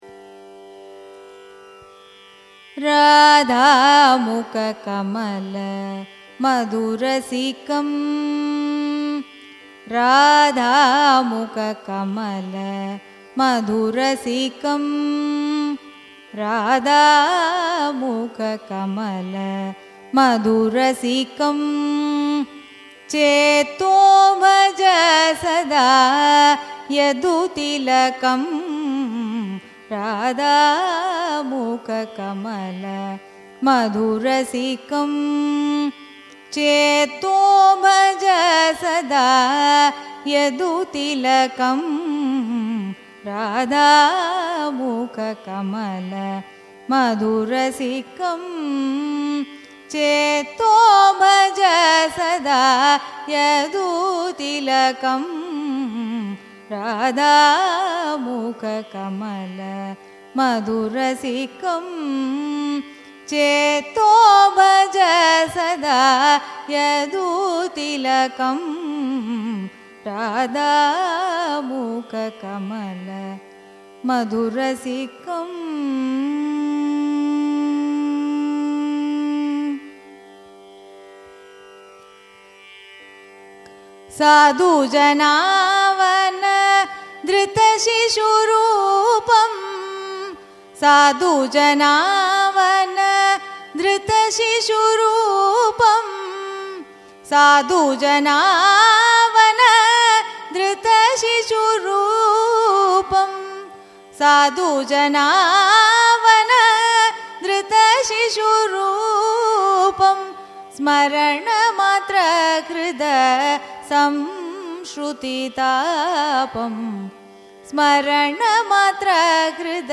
Raga: Hindustani Kapi Tala: Adi
carnatic_song-radha_mukha_kamala-hindustani_kapi-adi-papanasam_sivan.mp3